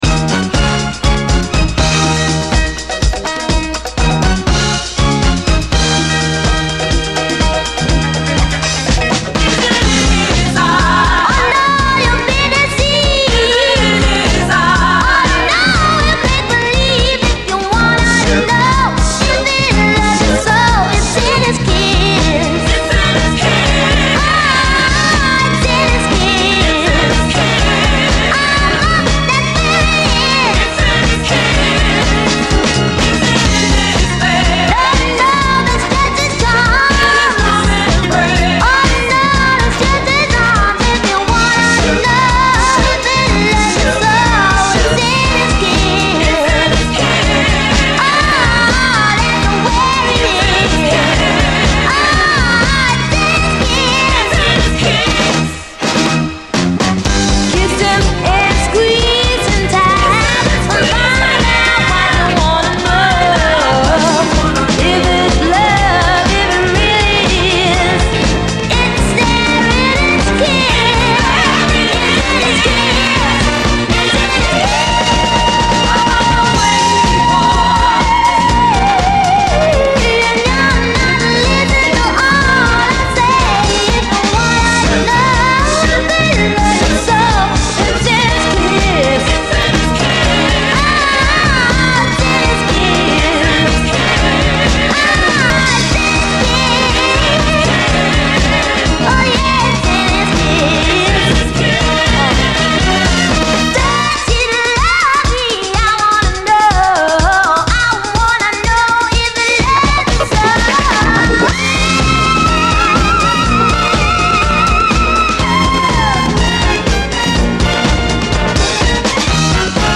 SOUL, 70's～ SOUL, 7INCH
元気いっぱいのグルーヴィー・アレンジ